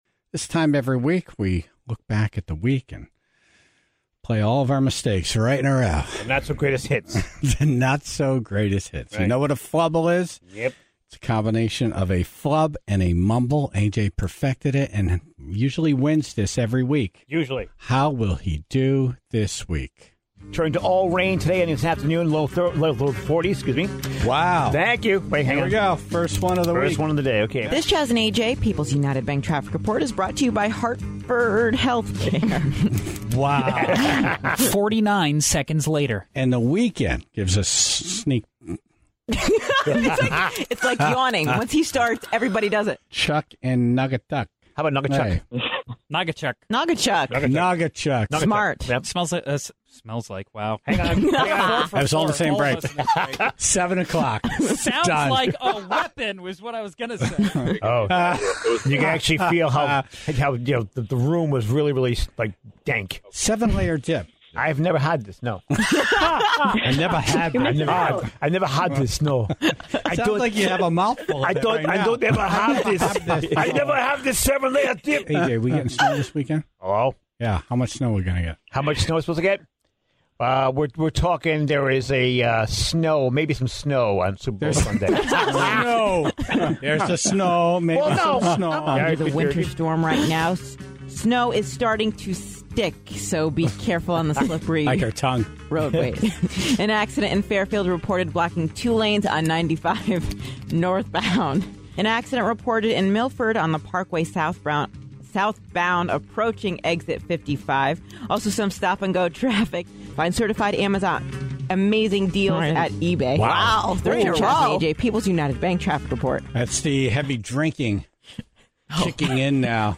This week's flubble montage featured a full spectrum of the show,